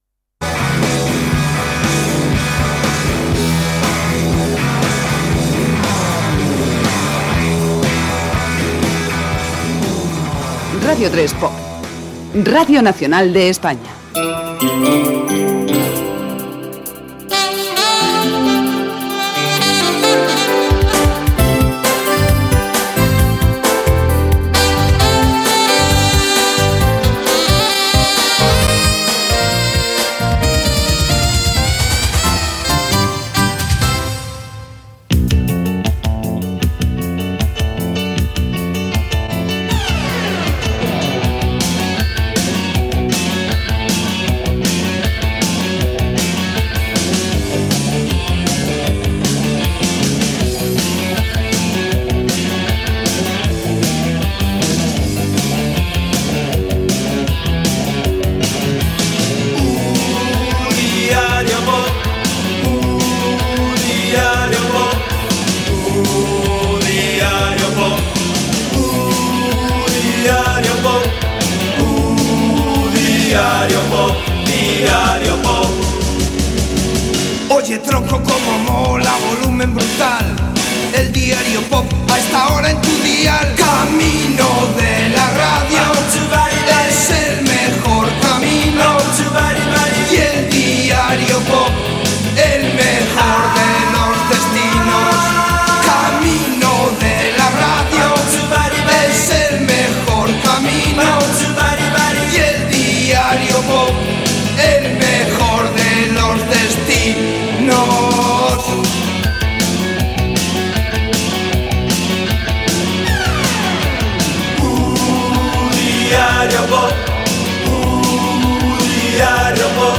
Indicatiu de la ràdio, sintonia cantada del programa, equip, tema musical, comentari i tema musical
Musical
FM